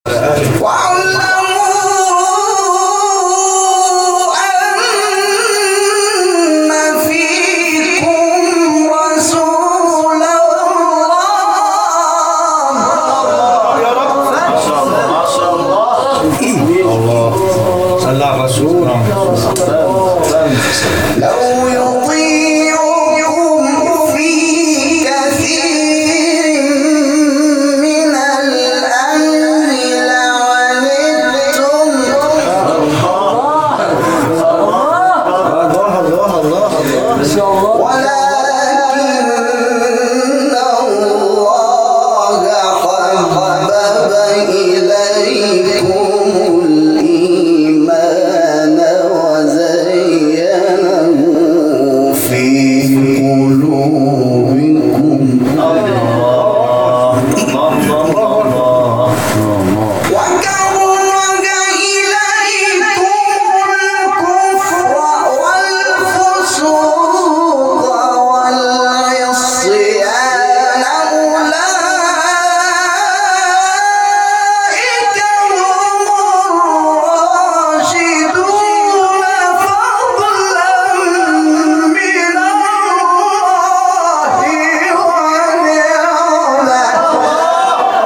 شبکه اجتماعی: فرازهای صوتی از تلاوت قاریان ممتاز کشور را می‌شنوید.
سوره حجرات در مقام سگاه